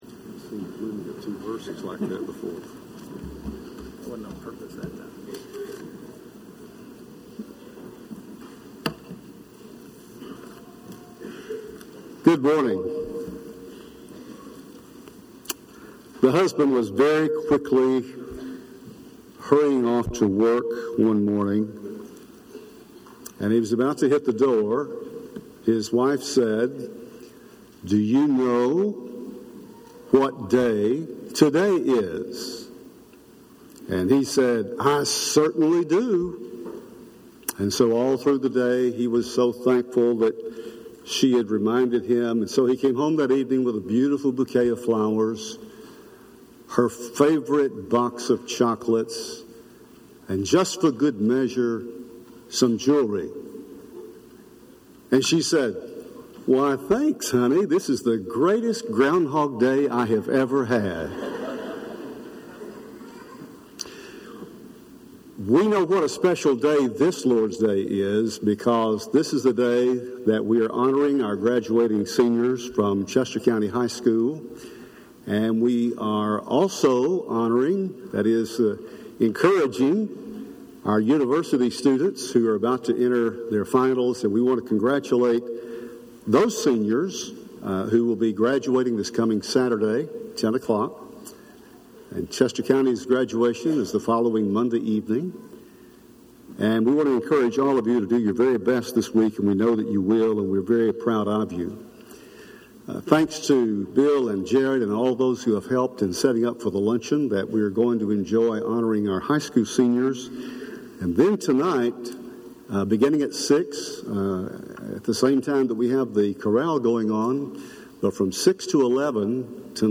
When Jesus Extended the Invitation – Henderson, TN Church of Christ